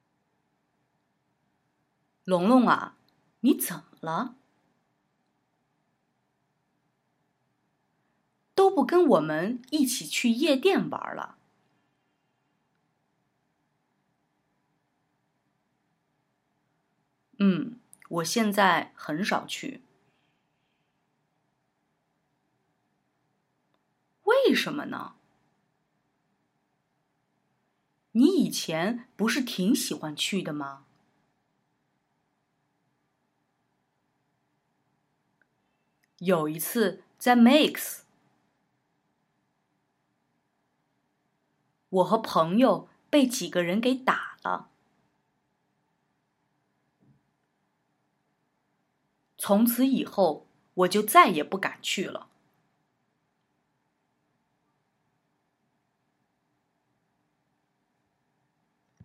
Übung 1: Hören und Satz für Satz nachsprechen